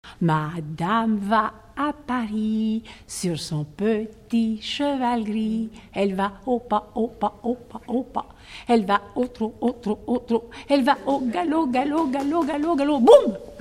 formulette enfantine : sauteuse
Pièce musicale inédite